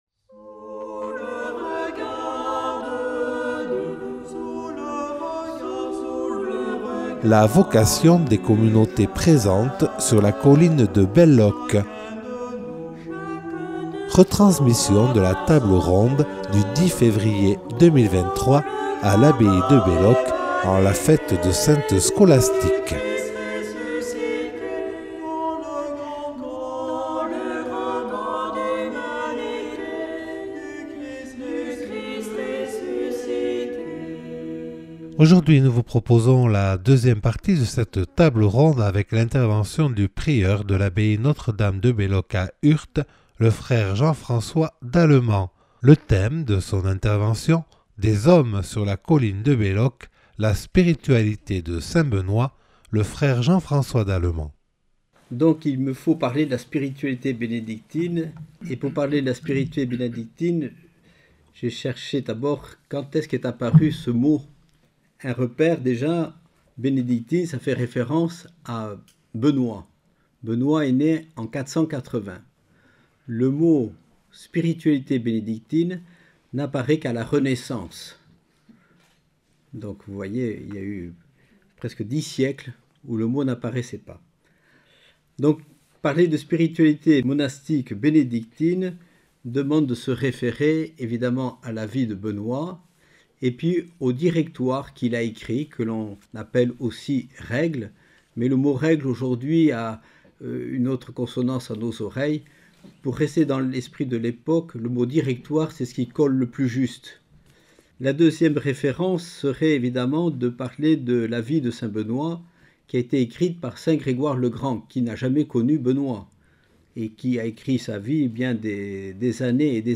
Retransmission de la table ronde du 10 février 2023 à l’abbaye de Belloc.